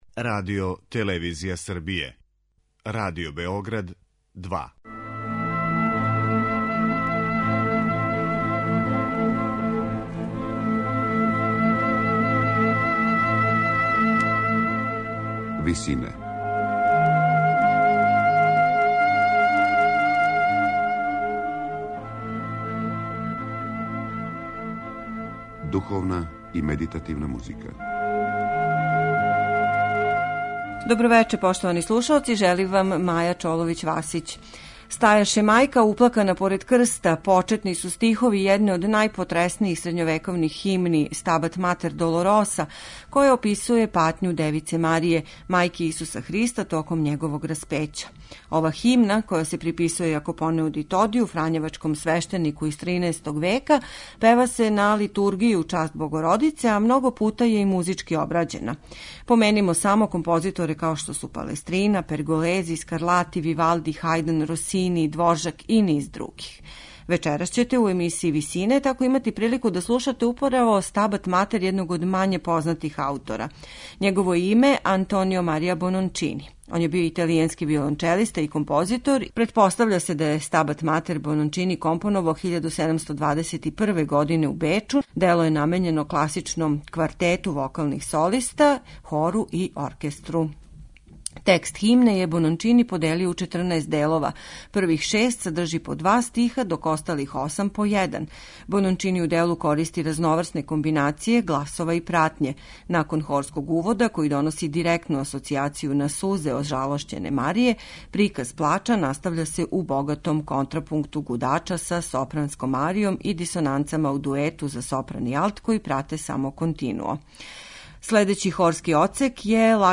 Извођачи су чланови хора катедрале Сент Џон (St. John) у Кембриџу и камерни оркестар Филомузика из Лондона.